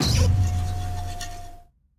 Cri de Hurle-Queue dans Pokémon Écarlate et Violet.